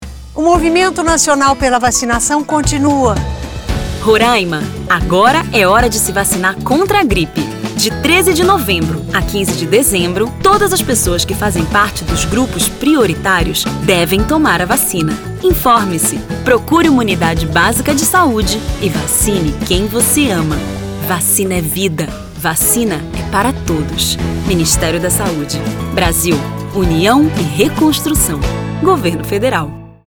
Roraima: Spot - Vacinação Contra a Gripe em Roraima - 30seg .mp3